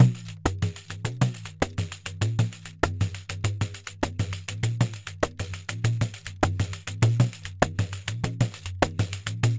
Pandeiro 1_Samba 100_1.wav